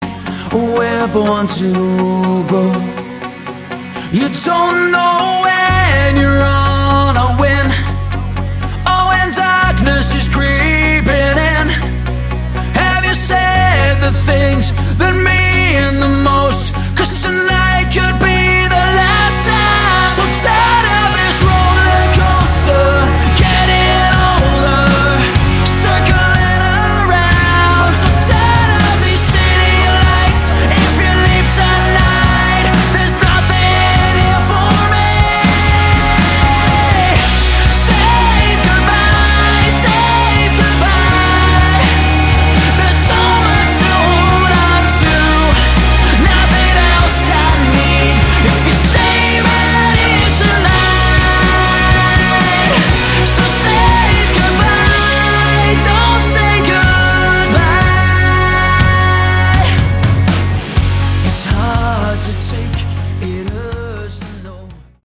Category: Hard Rock
vocals
lead guitar
rhythmn guitar
bass
drums